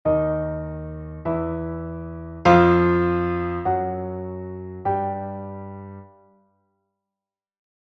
der Ton F, Notation
der-Ton-F.mp3